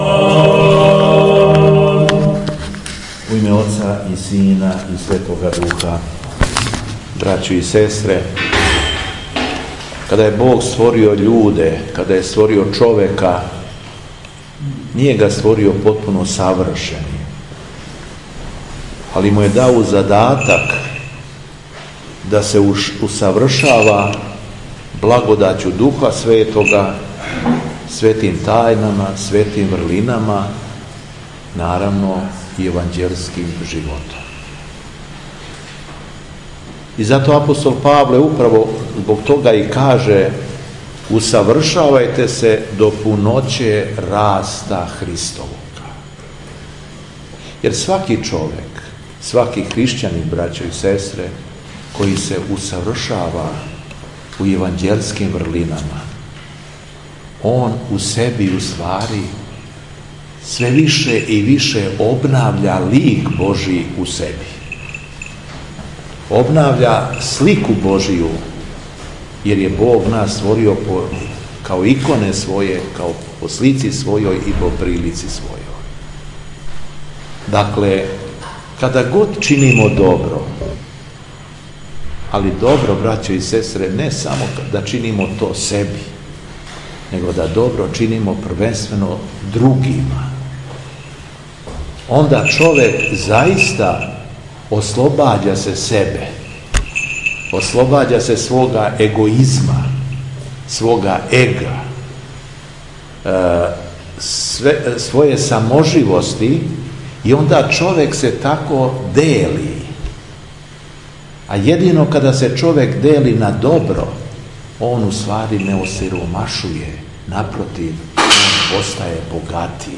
СВЕТА АРХИЈЕРЕЈСКА ЛИТУРГИЈА У МАНАСТИРУ ТРЕСИЈЕ
Беседа Његовог Преосвештенства Епископа шумадијског г. Јована